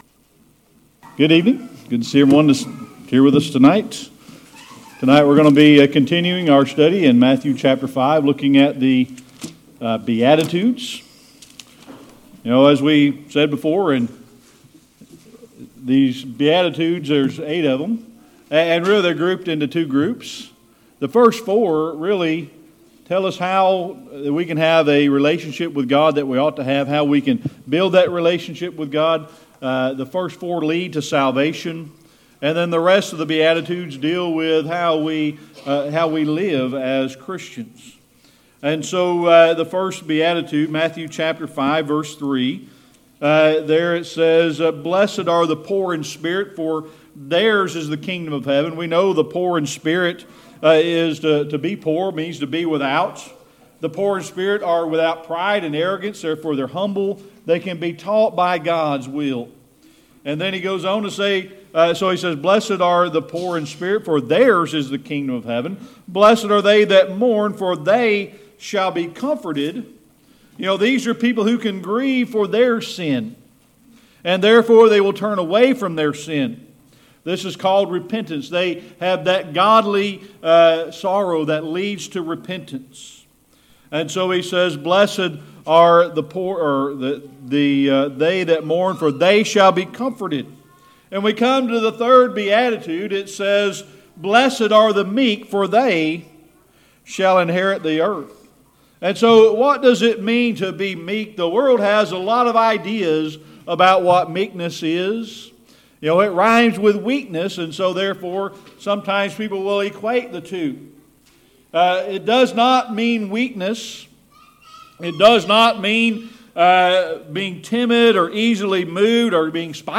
Sermon Archives
Matthew 5:5 Service Type: Sunday Evening Worship We're going to continue our study tonight of Matthew chapter 5 looking at the Beautitudes.